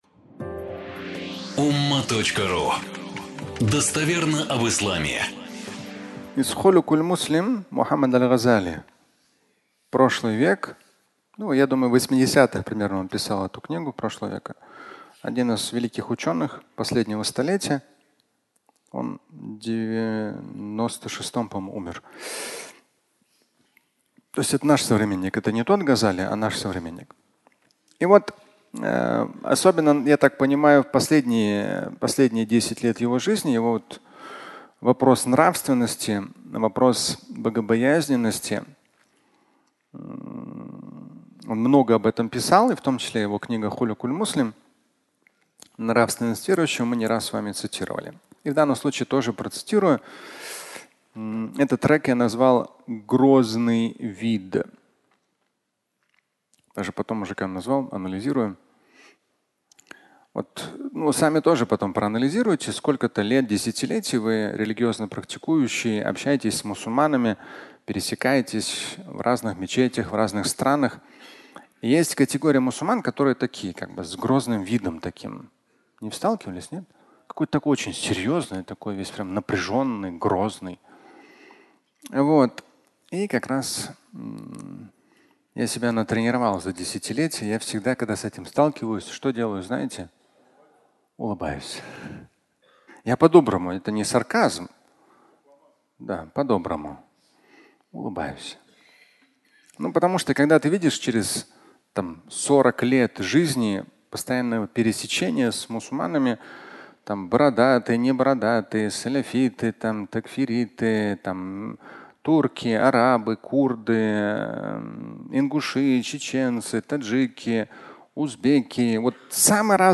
Грозный вид (аудиолекция)
Фрагмент пятничной лекции